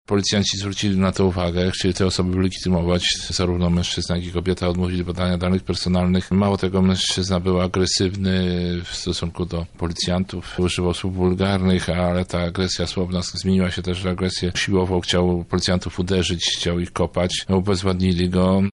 Gazem za przechodzenie na czerwonym. Jest komentarz policji